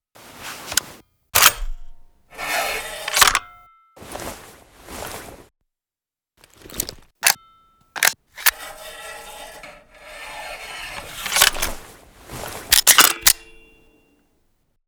barrel_exchange.wav